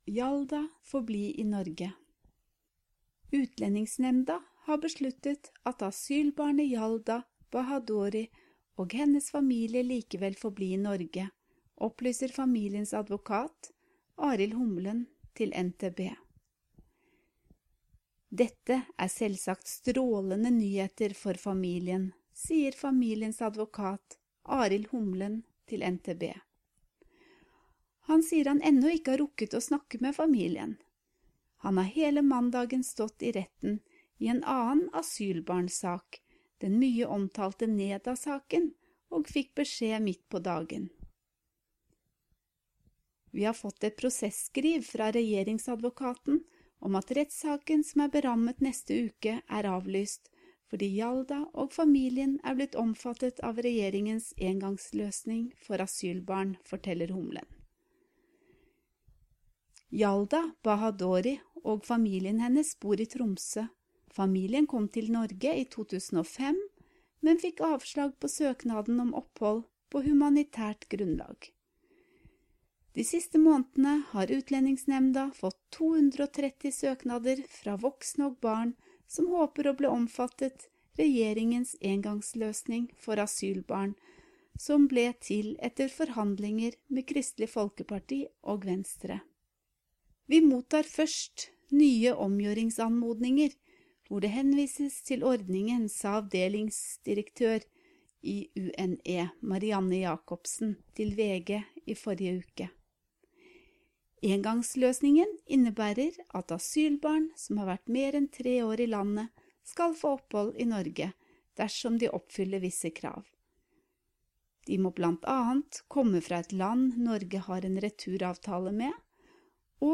Learn Norwegian by listening to a native Norwegian while you’re reading along.